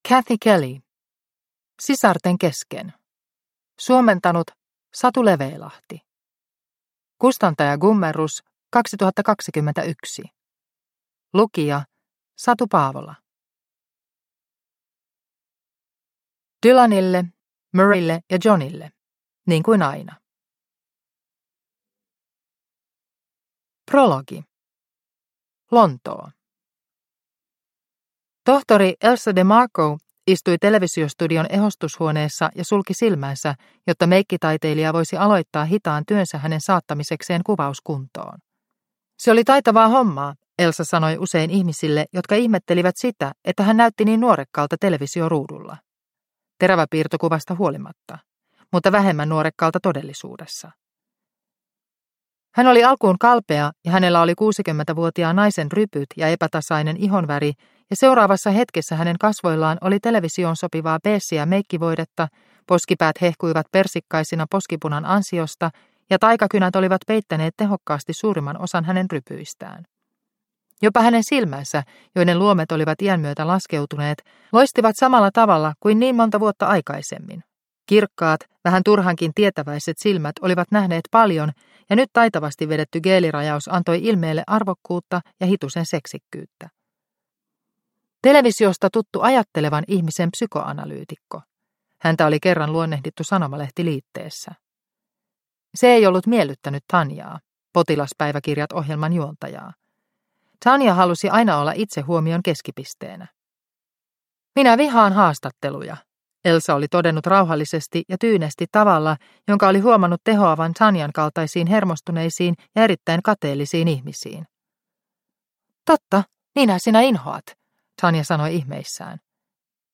Sisarten kesken – Ljudbok – Laddas ner